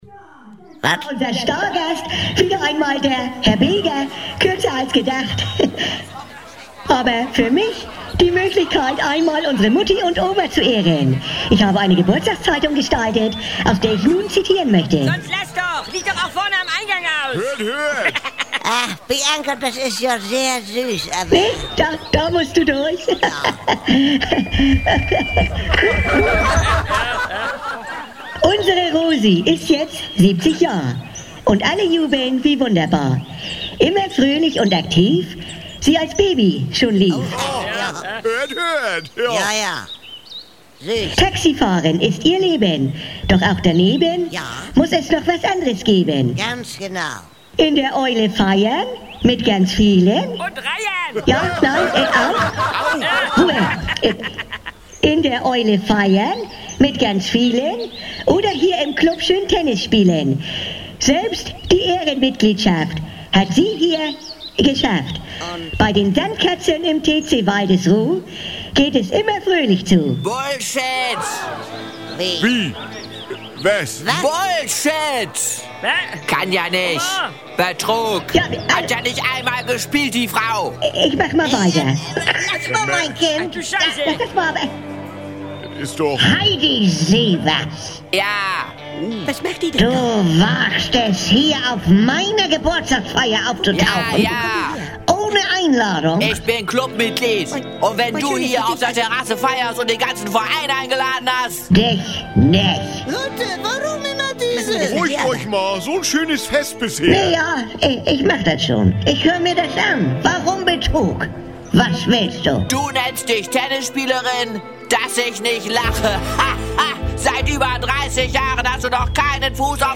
Themenwelt Literatur Comic / Humor / Manga Humor / Satire